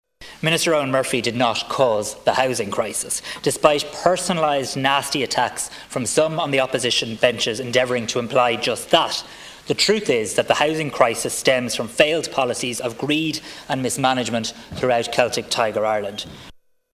The Housing Minister survived a motion of no confidence last night after a stormy debate in the Dáil.
Health Minister Simon Harris launched a robust defence of Eoghan Murphy: